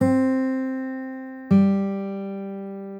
In the first measure, we have a perfect fifth. From C to G. In the second measure, we play from G to C.
Komplementaerintervalle-Quinte-abwaerts.ogg